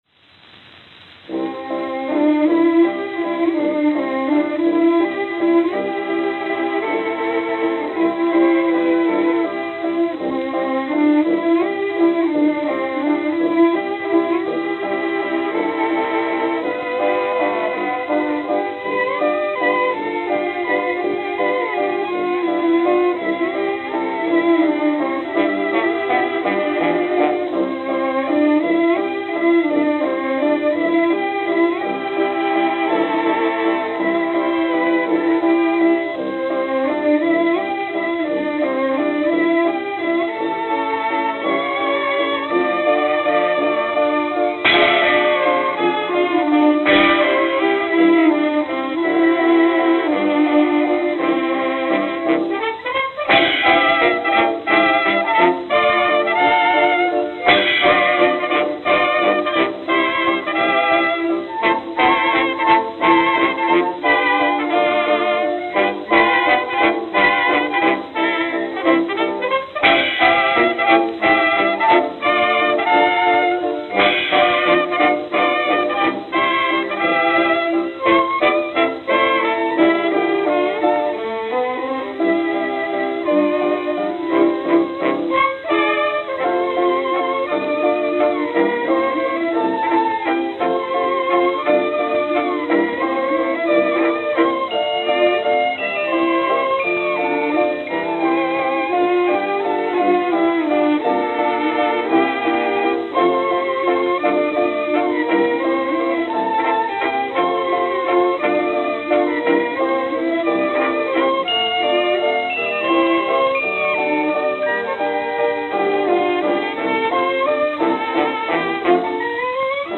Note: Weak pressing.